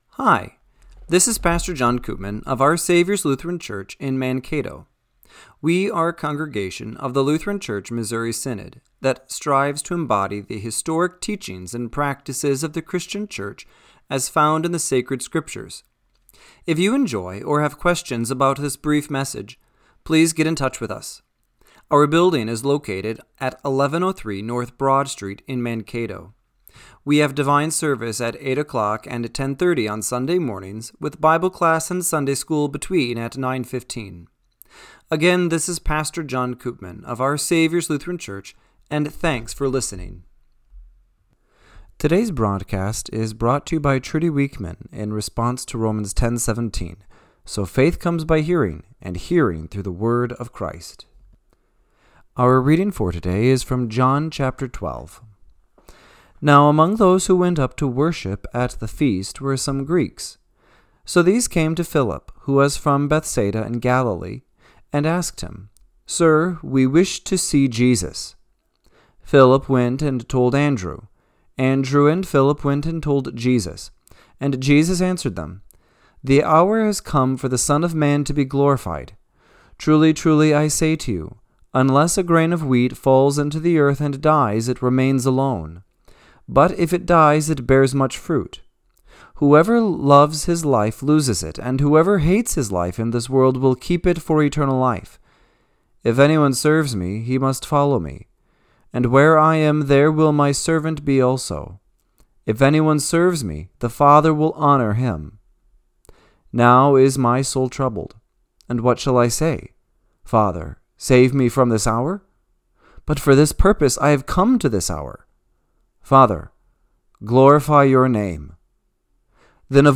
Radio-Matins-9-14-25.mp3